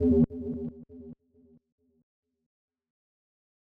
KIN Hollow Bass E.wav